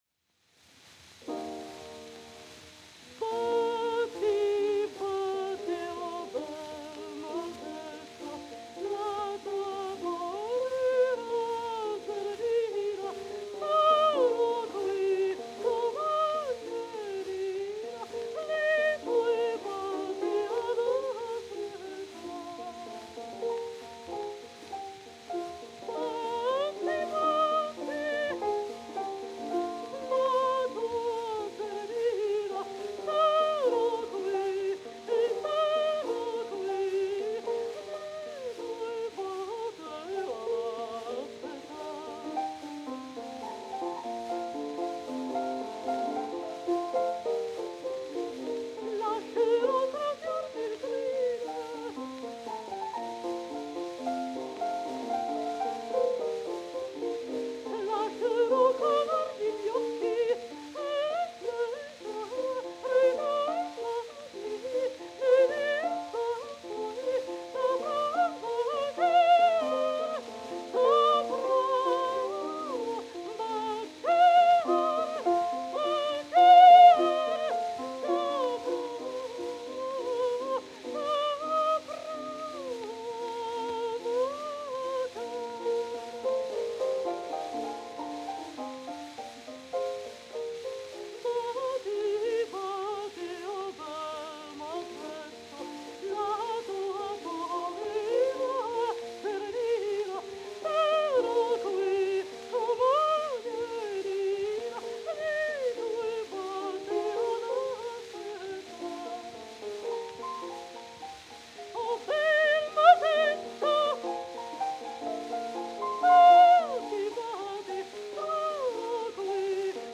Adelina Patti, soprano; Landon Ronald, piano 1905.